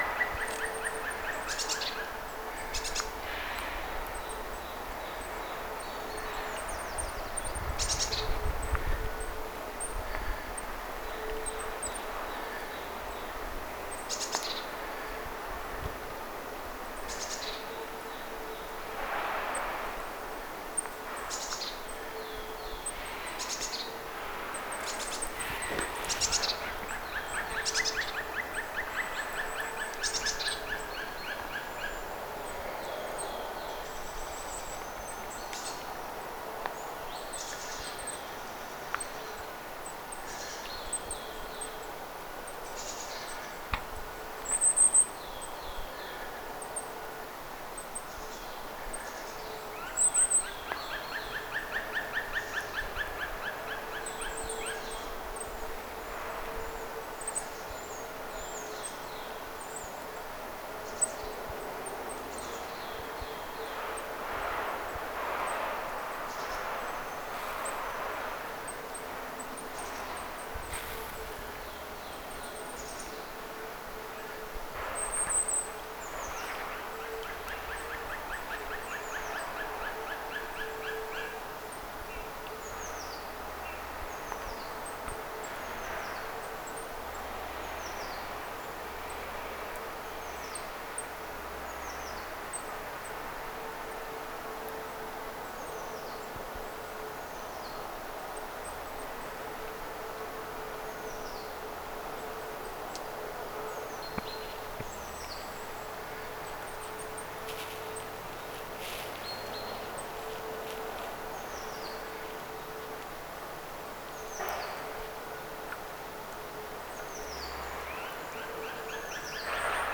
lauloi ahkeraan palokärki
Siis palokärjellä on vähän eri
palokärki laulaa
kevaisessa_metikossa_palokarjen_laulua.mp3